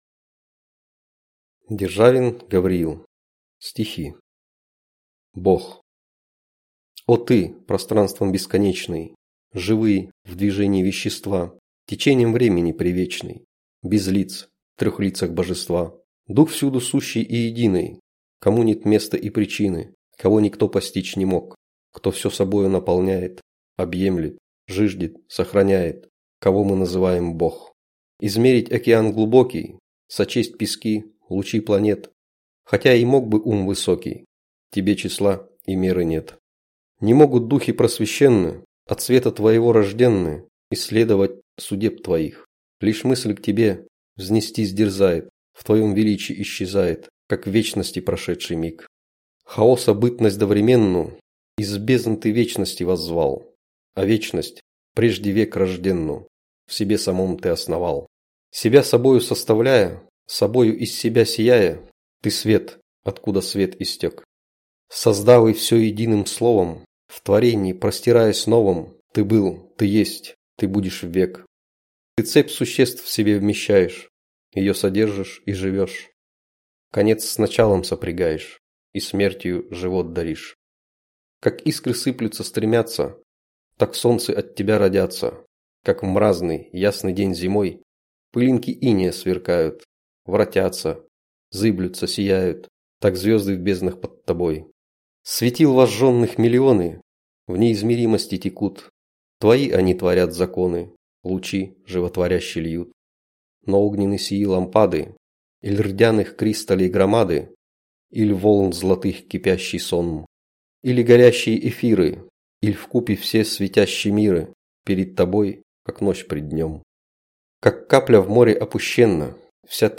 Аудиокнига Стихи | Библиотека аудиокниг